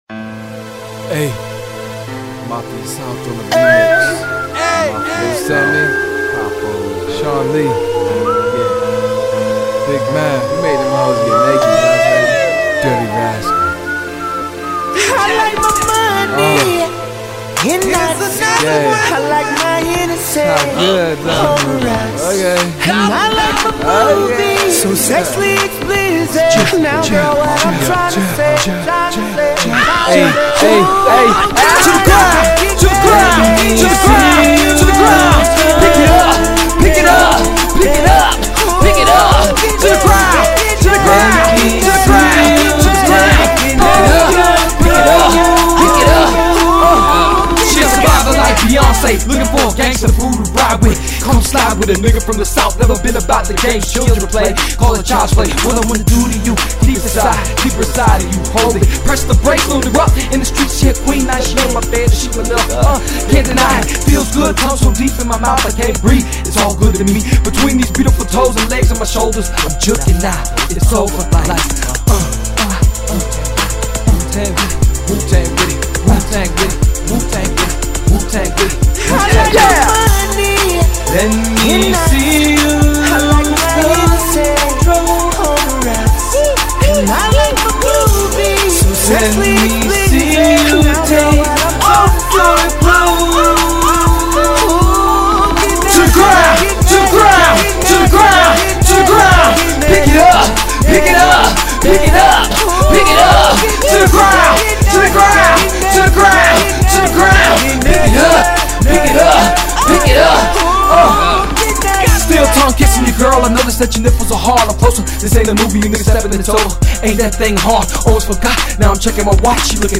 rap Gangsta Rap Miami rap
hip hop